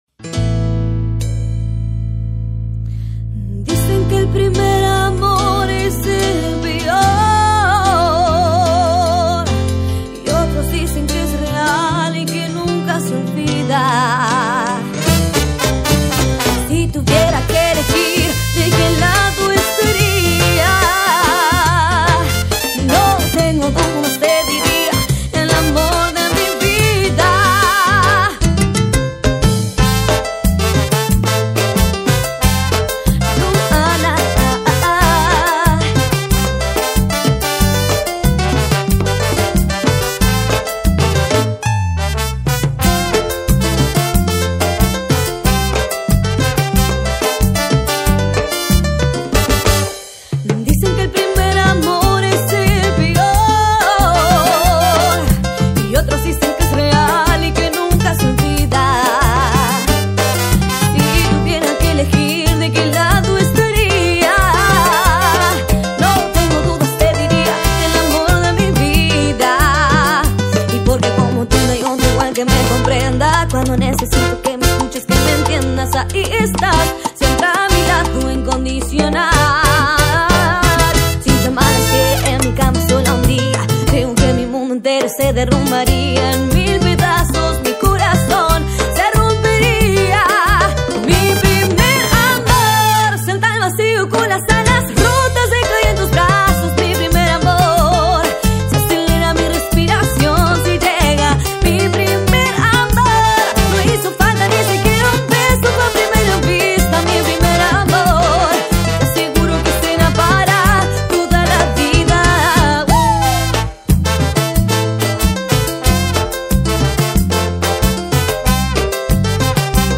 Carpeta: Cumbia y + mp3
en vivo